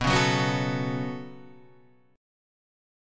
B7sus2 chord